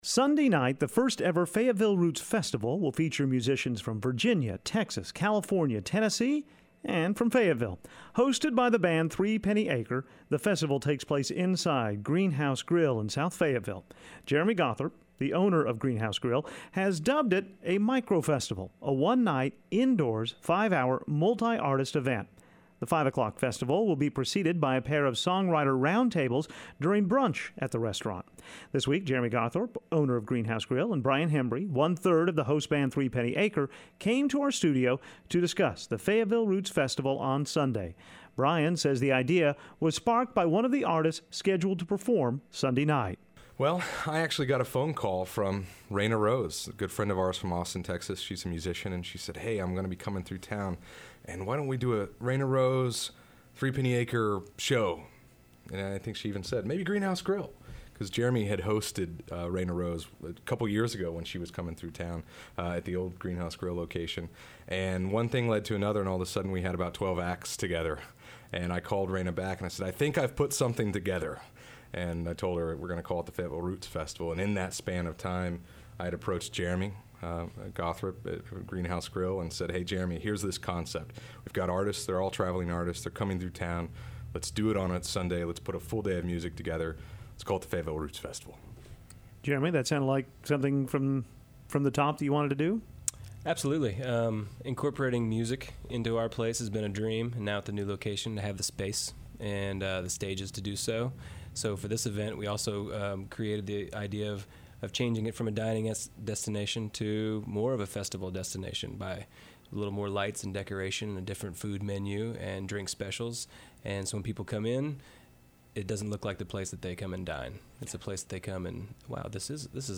Roots Festival Roots Festival.mp3 The first-ever Fayetteville Roots Festival is scheduled for Sunday at Greenhouse Grille. We talked with organizers about how the multi-artist musical event was planned and hear some of the musicians involved.